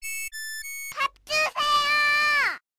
알림음 8_밥주세요.ogg